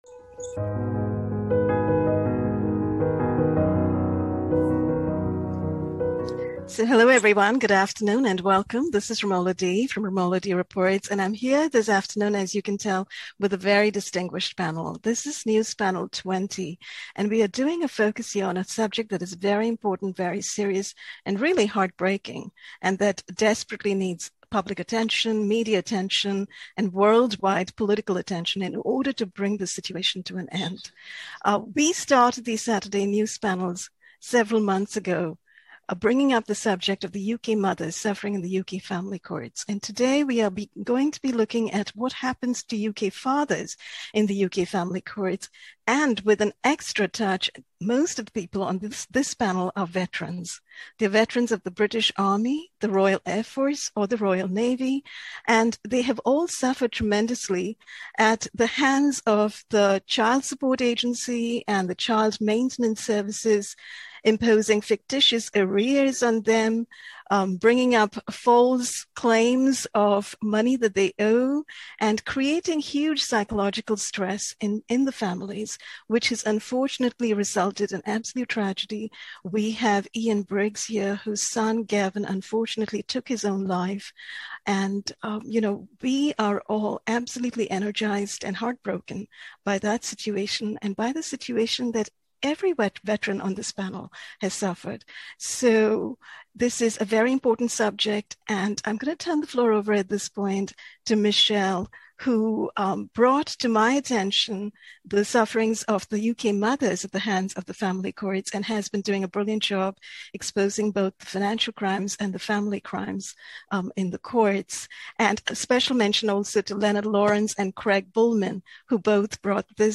News Panel 20-Report 246: UK Fathers and Veterans Report Psych Warfare, Child Support Agency Crimes
Probing and informative conversation on crimes of extortion, aggressive collections, false-claims of arrears, bullying and harassment by Child Support Agency and Child Maintenance Support services, essentially comprising extortion and asset theft, assisted by a network of family court judges, lawyers and loan funder...